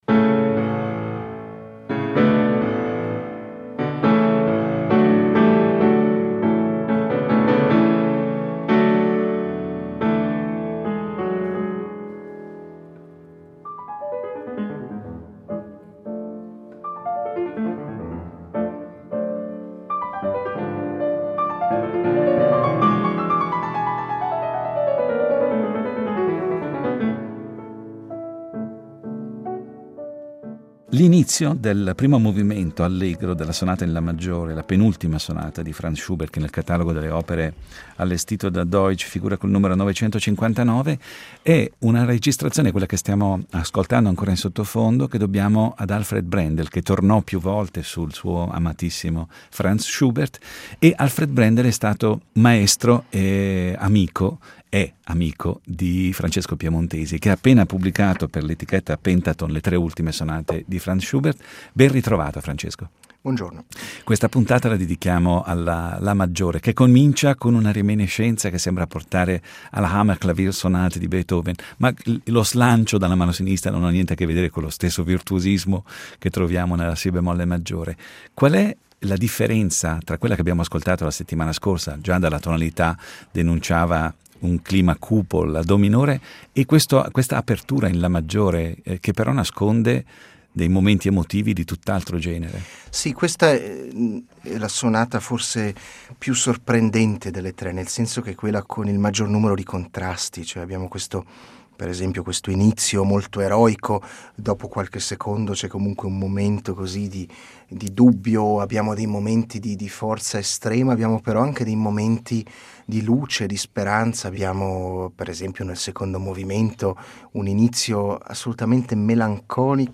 Incontro con Francesco Piemontesi